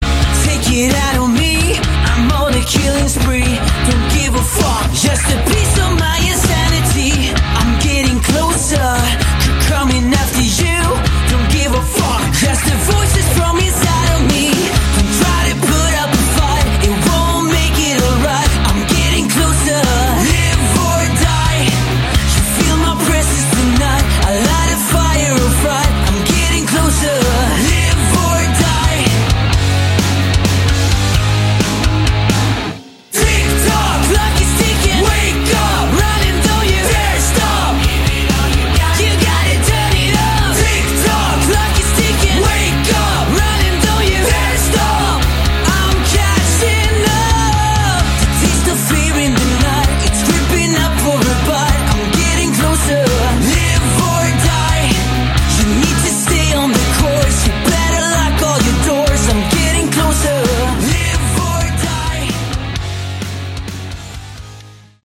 Category: Sleaze Glam / Hard Rock
drums
guitar, Lead vocals
guitar, backing vocals
Bass, backing vocals